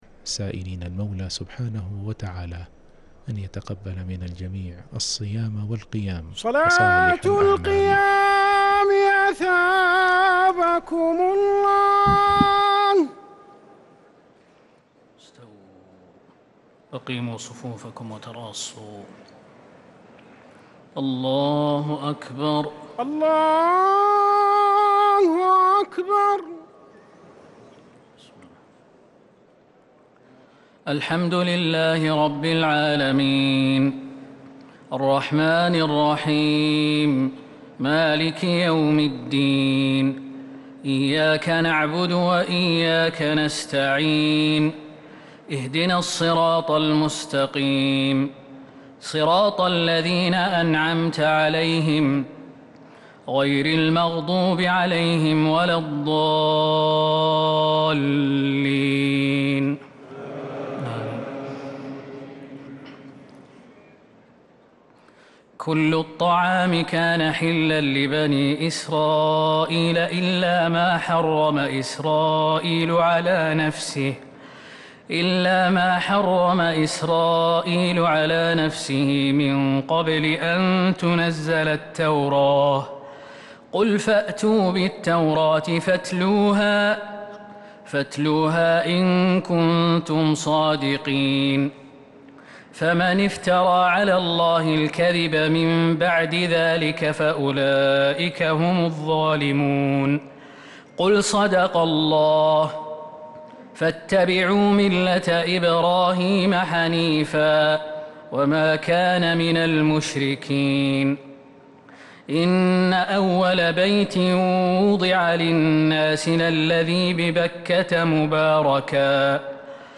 تراويح رمضان
تِلَاوَات الْحَرَمَيْن .